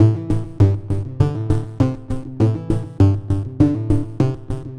tx_synth_100_bouncy_CMaj1.wav